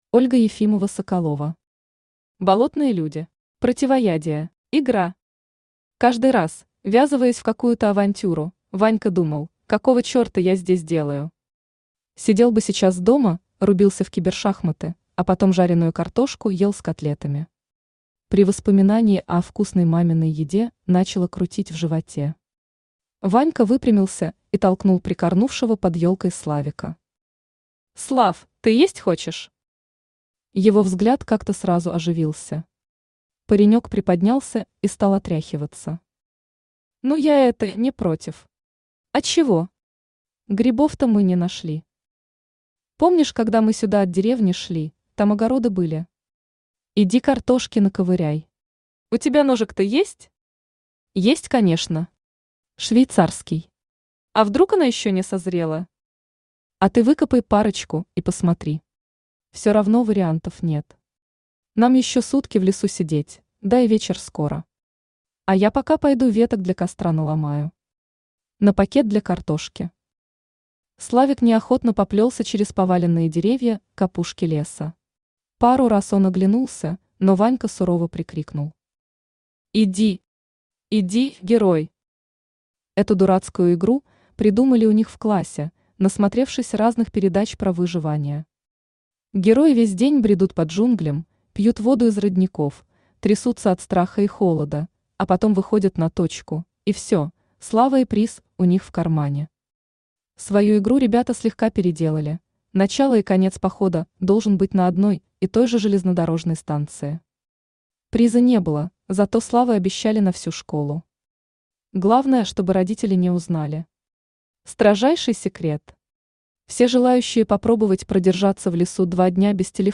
Аудиокнига Болотные люди. Противоядие | Библиотека аудиокниг
Противоядие Автор Ольга Ефимова-Соколова Читает аудиокнигу Авточтец ЛитРес.